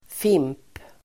Ladda ner uttalet
fimp substantiv, end , butt Uttal: [fim:p] Böjningar: fimpen, fimpar Synonymer: cigarettrest Definition: rest av en cigarett; cigarettstump Sammansättningar: cigarettfimp (cigarette end, cigarette butt)